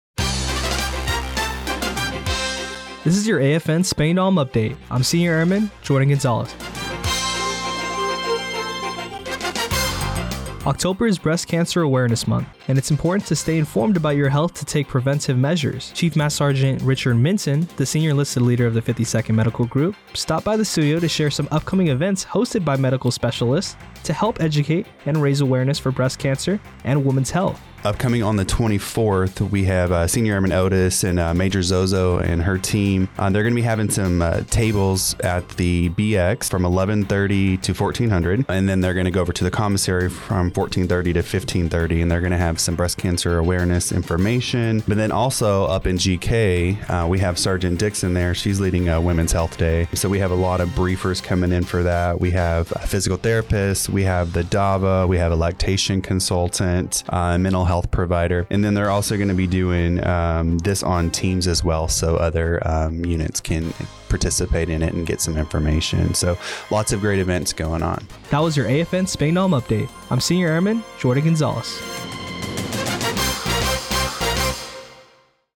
The following was the radio news report for AFN Spangdahlem for Oct. 22, 2024.